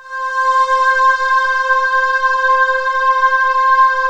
VOCODINGC5-R.wav